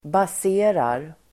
Uttal: [bas'e:rar]